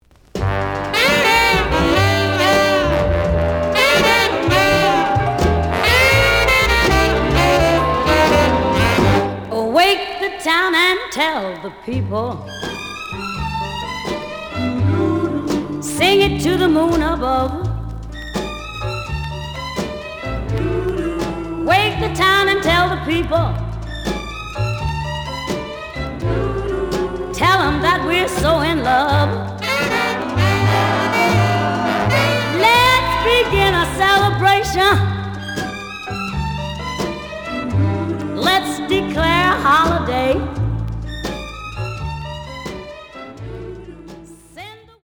The audio sample is recorded from the actual item.
●Genre: Vocal Jazz